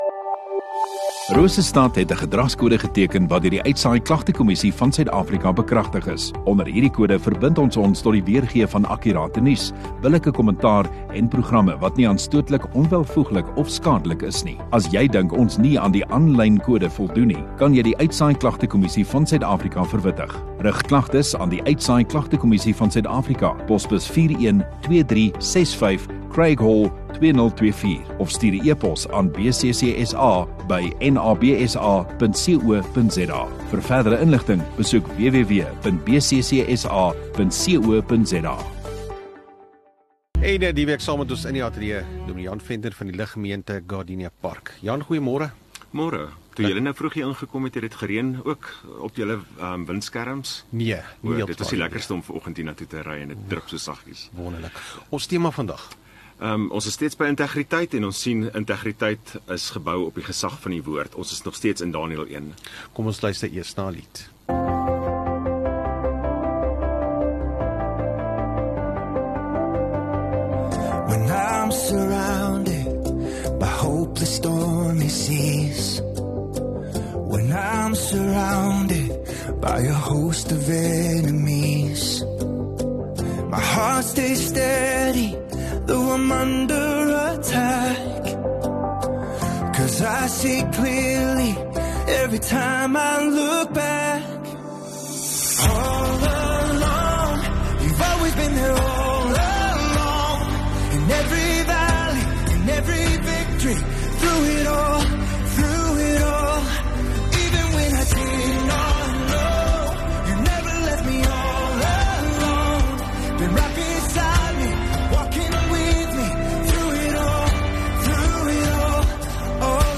8 Nov Vrydag Oggenddiens